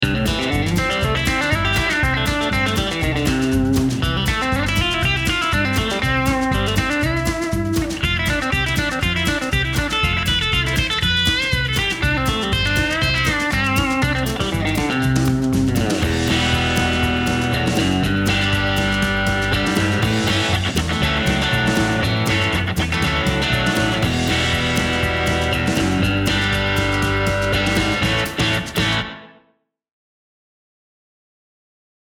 This Vintage Tele lead pickup uses alnico 2 rod magnets to provide a softer treble attack for players who want Telecaster tone without excess bite.
APTL-1_CRUNCH_BAND_SM